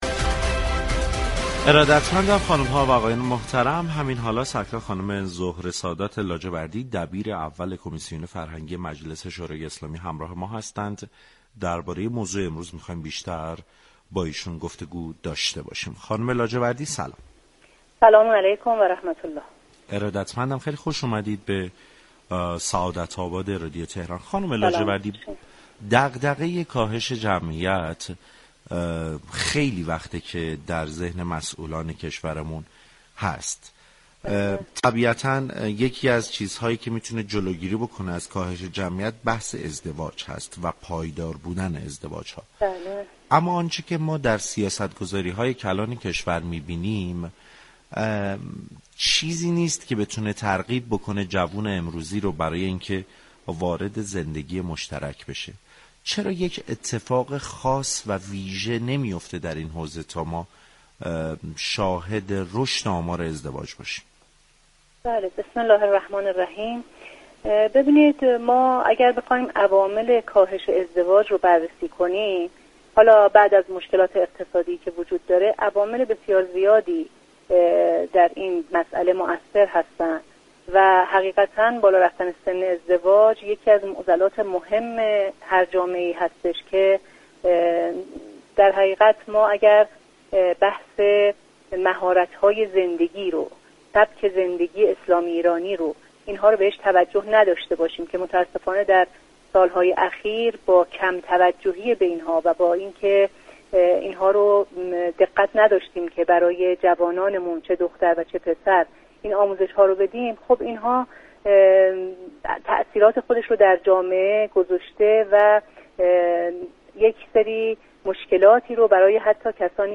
به گزارش پایگاه اطلاع رسانی رادیو تهران، زهره سادات لاجوردی دبیر اول كمیسیون فرهنگی مجلس یازدهم در گفتگو با برنامه سعادت آباد رادیو تهران در خصوص عوامل كاهش ازدواج در كشور طی سال های اخیر گفت: بالارفتن سن ازدواج یكی از معضلات مهم جامعه است.